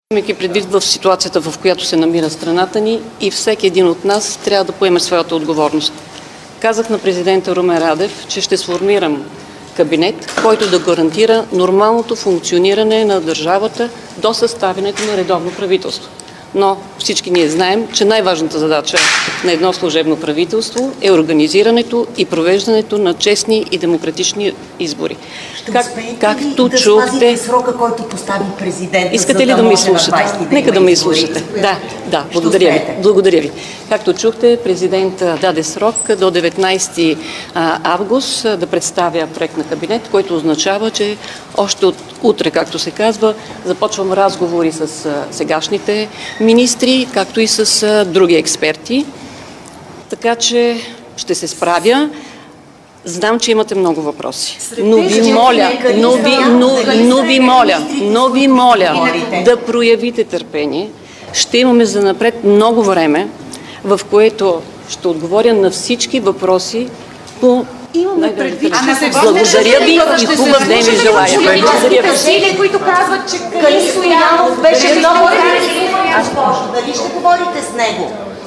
10.30 - Връчване на мандат за съставяне на правителство на Горица Грънчарова-Кожарева.
Директно от мястото на събитието